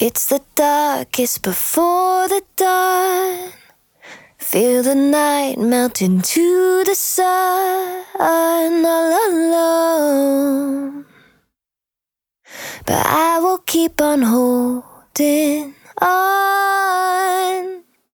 Vocals without Delay